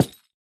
Minecraft Version Minecraft Version 1.21.5 Latest Release | Latest Snapshot 1.21.5 / assets / minecraft / sounds / block / cherry_wood_hanging_sign / break1.ogg Compare With Compare With Latest Release | Latest Snapshot
break1.ogg